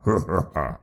AV_pumpkin_short.ogg